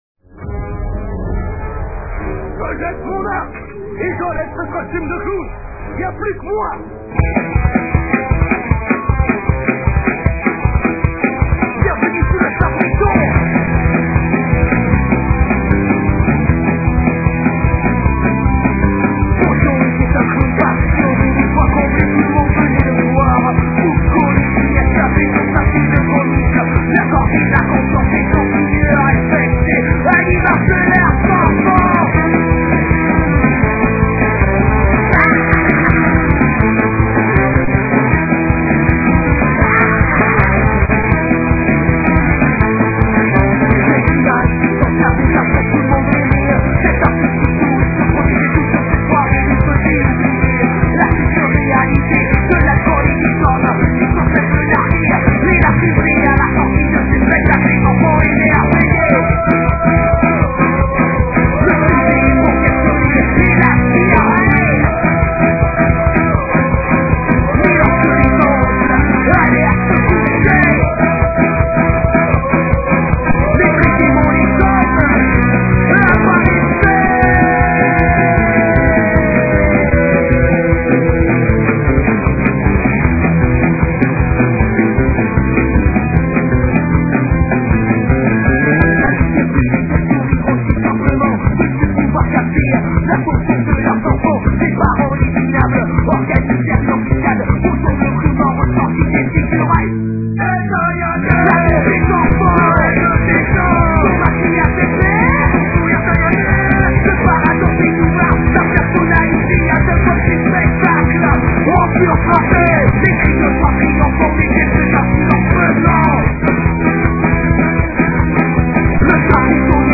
Les fichiers sont en mp3 et de qualité assez mauvaise .